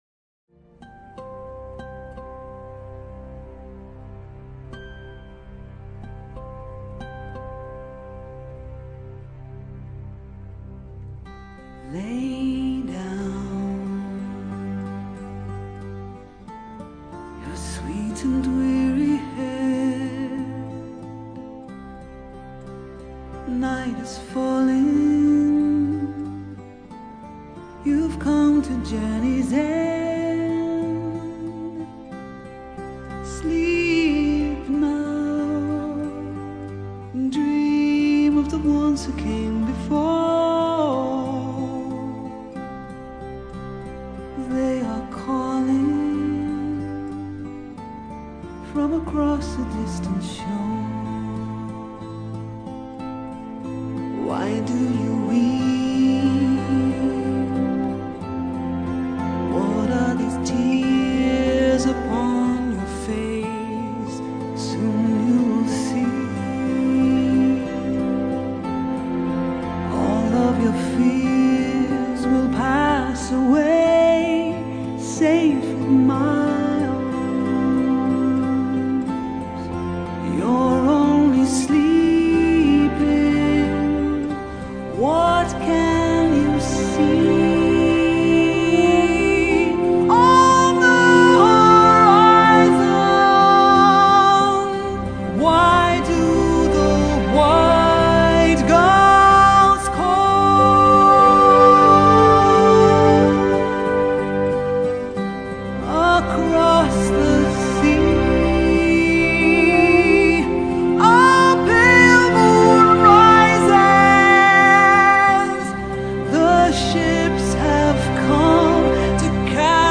MP3 / Chant - Guitare / ....Video